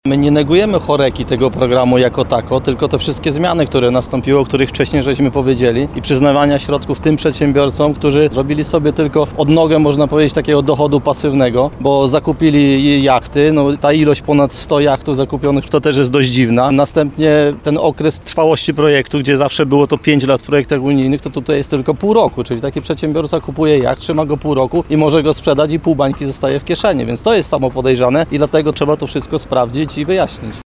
Parlamentarzyści i samorządowcy Prawa i Sprawiedliwości z Sądecczyzny krytykują rząd za aferę z dzieleniem pieniędzy z Krajowego Planu Odbudowy. Na rynku w Nowym Sączu żądali wyjaśnień w tej sprawie.